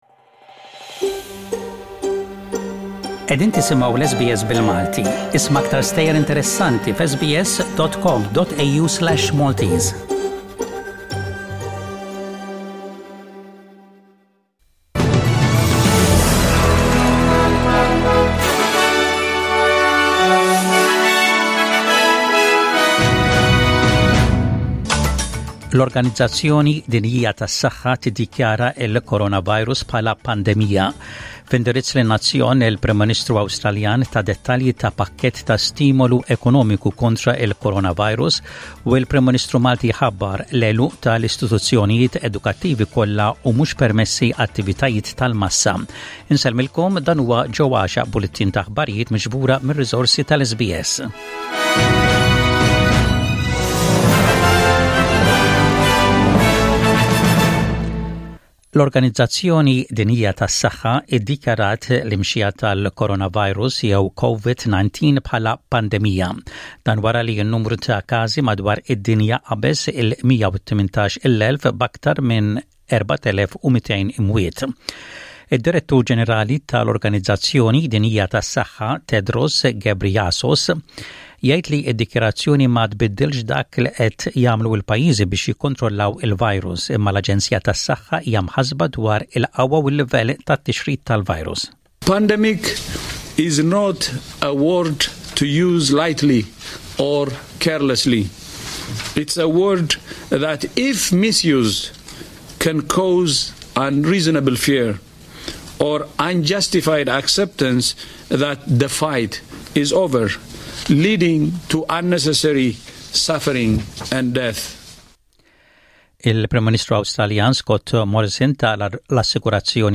SBS Radio | Maltese News: 13/03/20